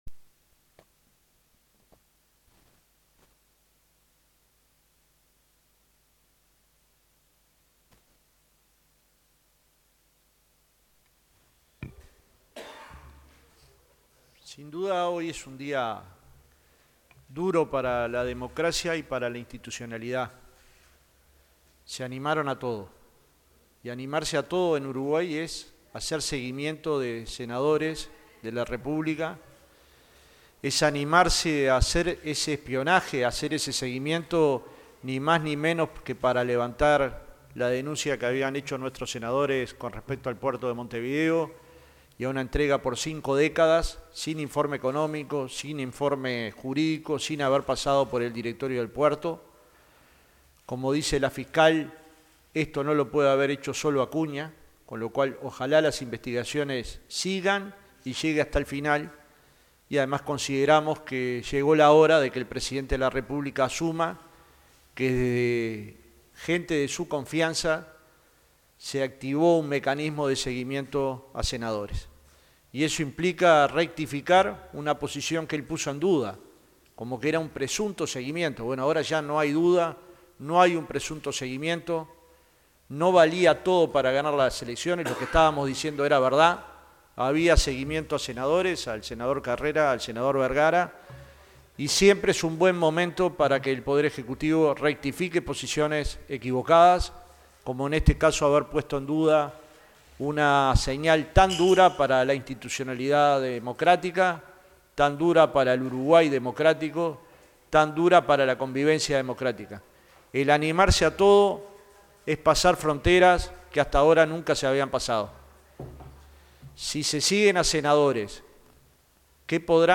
Conferencia sobre espionaje a senadores FA
conferencia_FA_por_espionaje_.mp3